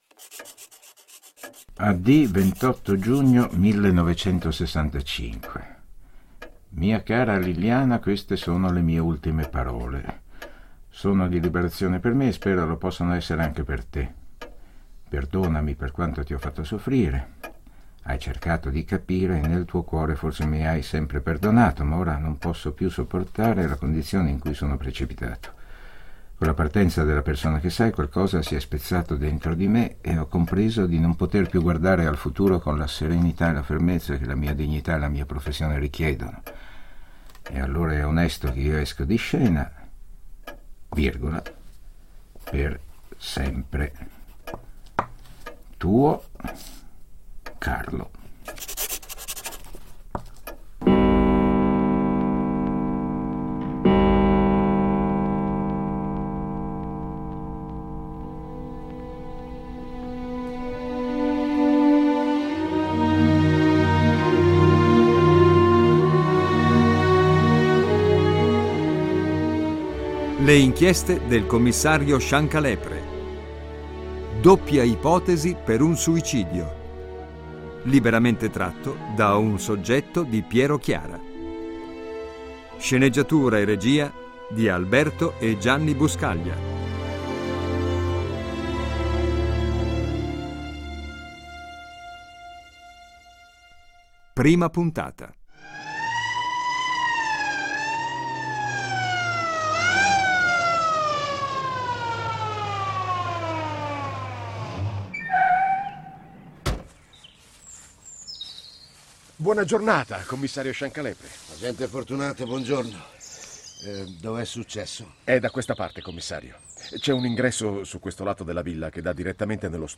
sceneggiato radiofonico